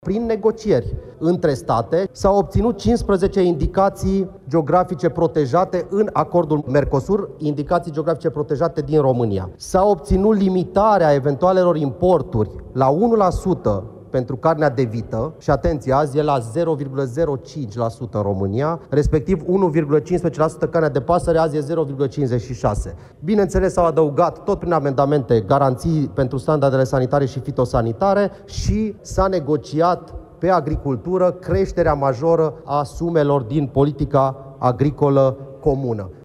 Ministrul Economiei, Irineu Darău: „Prin negocieri între state, s-au obținut 15 indicații geografice protejate în Acordul Mercosur, indicații geografice protejate din România”